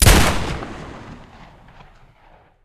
shotgun.ogg